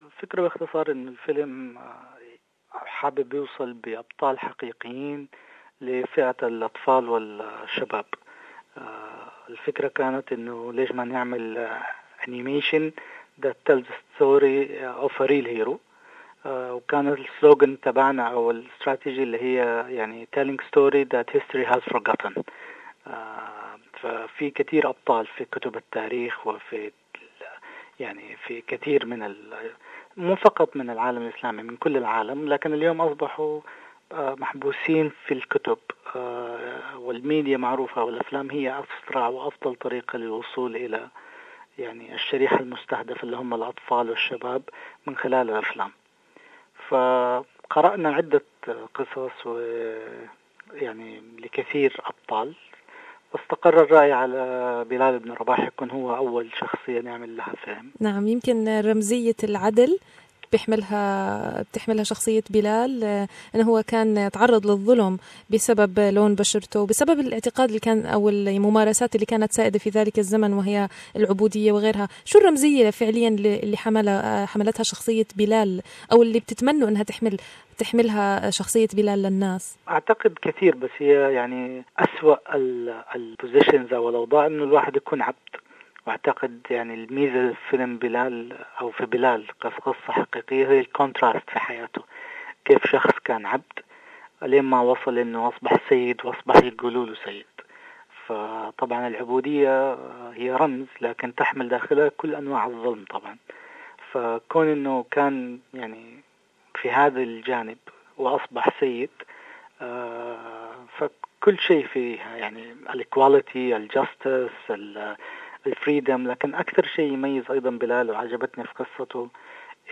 في هذا الحوار